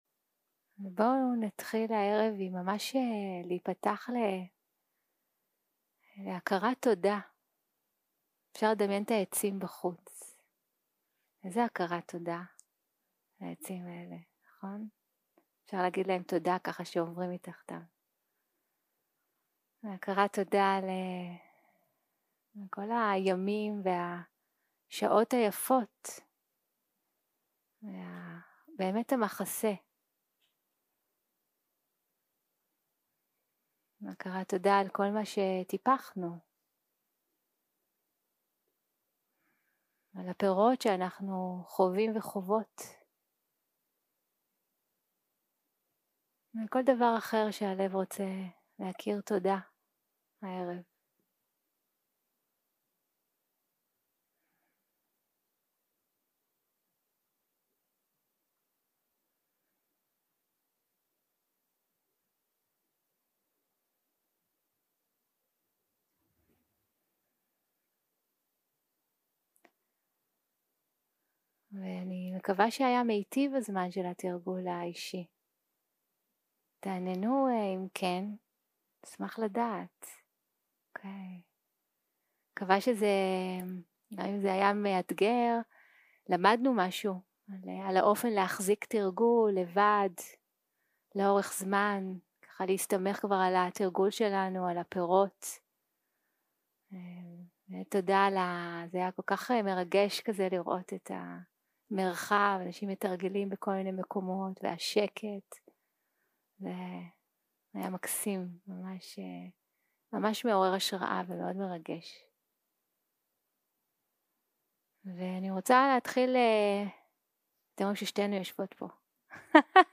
יום 7 - הקלטה 16 - ערב - שיחת דהרמה - התעוררות בעולם
סוג ההקלטה: שיחות דהרמה